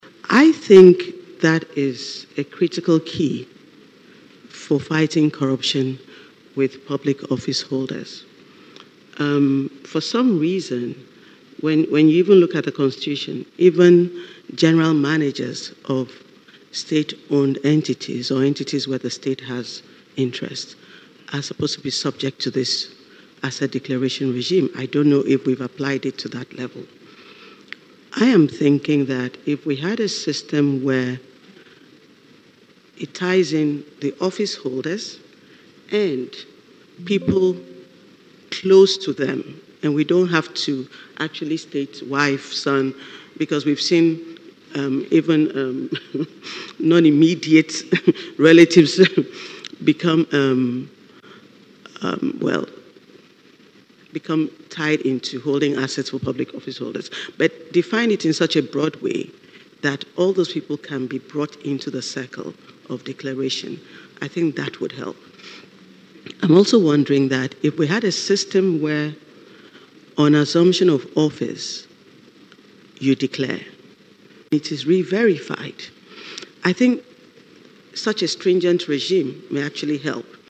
Speaking at the event, Charlotte Osei, who is also a member of the Constitutional Review Committee, emphasised the need for a critical review of the asset declaration regime.